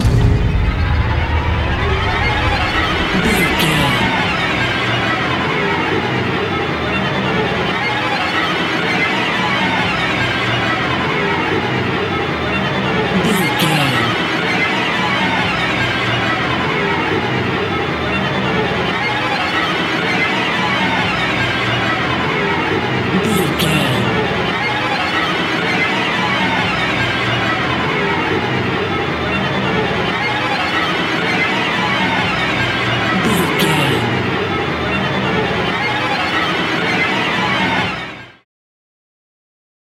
Horror String Suspense Hit.
In-crescendo
Atonal
tension
ominous
dark
eerie
thriller